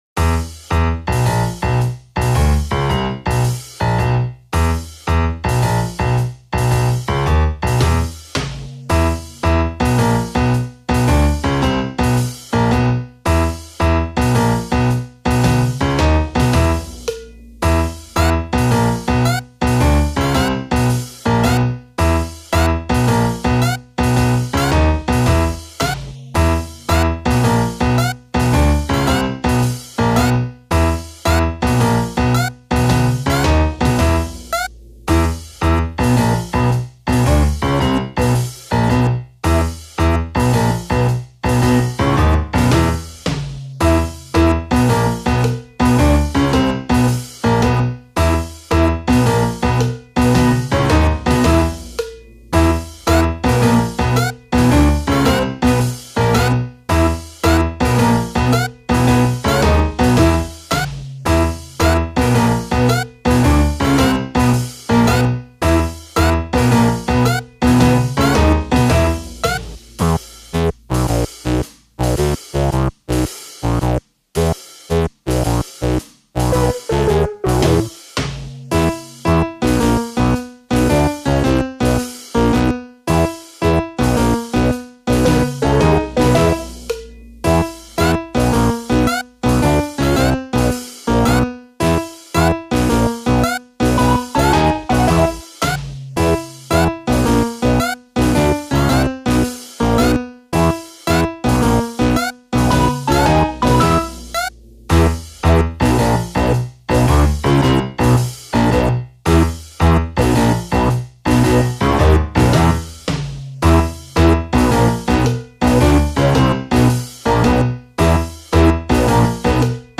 【用途/イメージ】　アニメ　コント　ドッキリ　忍び足　泥棒